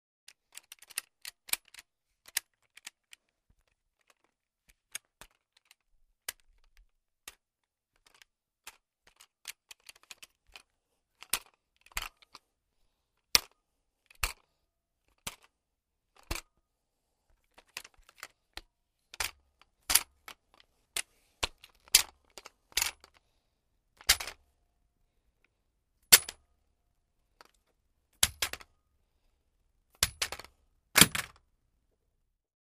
Звуки огнестрельного оружия
Сборка и разборка оружия, механические звуки